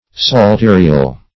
Psalterial \Psal*te"ri*al\, a. Of or pertaining to the psalterium.